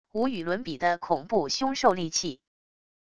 无与伦比的恐怖凶兽戾气wav音频